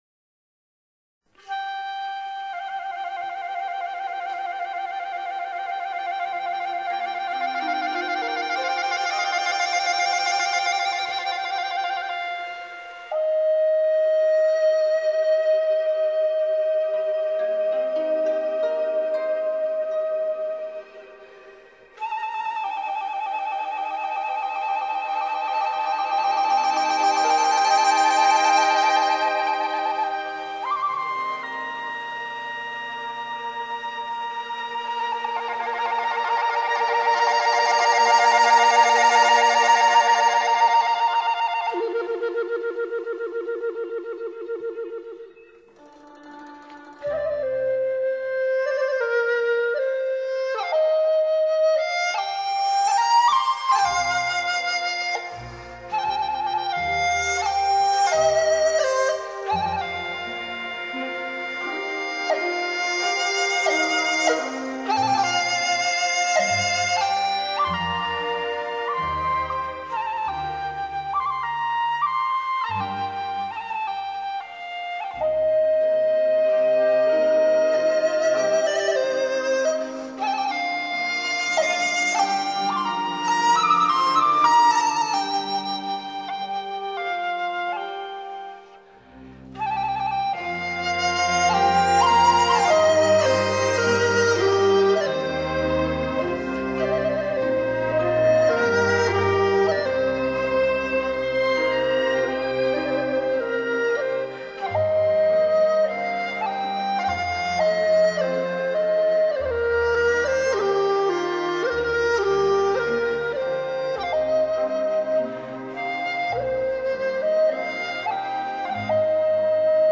笛子与乐队